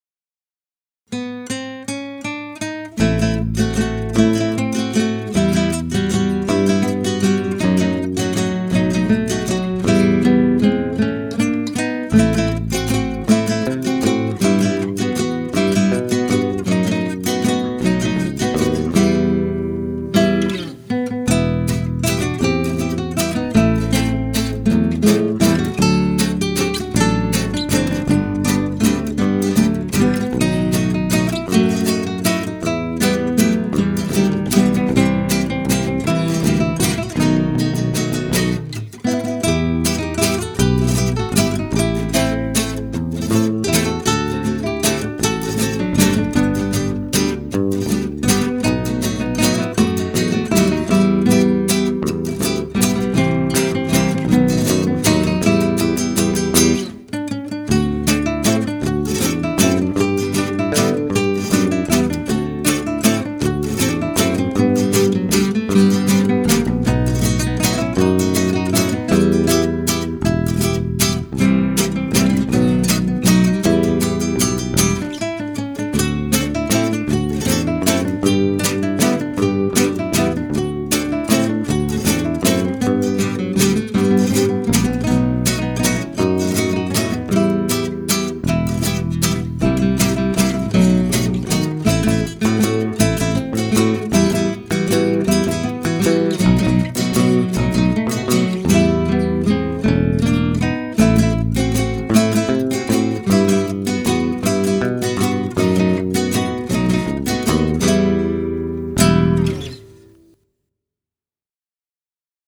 Guitar Arrangement
MAIN PAGE HOME City Halls Slow Wi-Fi Page Version La Foule Guitar Parts Guitar 1 Guitar 2A Guitar 2B Guitar 3 with diagrams Guitar 3 simplified Guitar 3 no diagrams Bass Main Track Download The track above is slower for practice purpose.